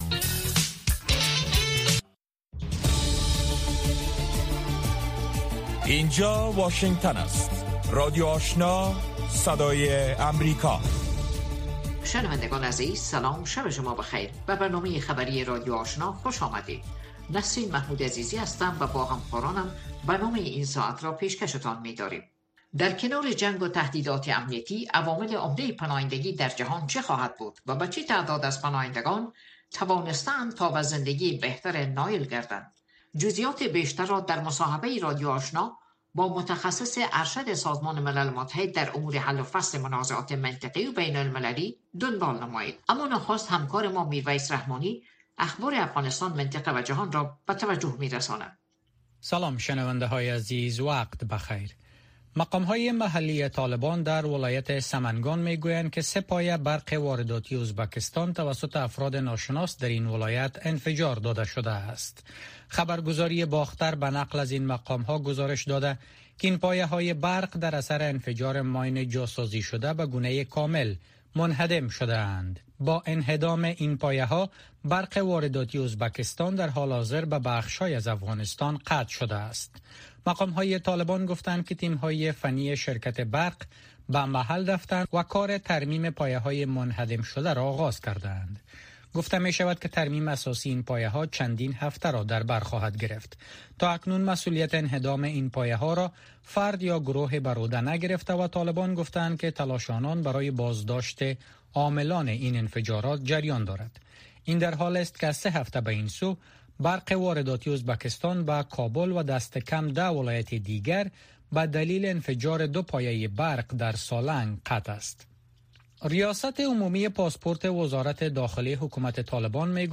نخستین برنامه خبری شب